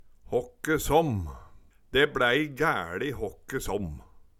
håkke såmm - Numedalsmål (en-US)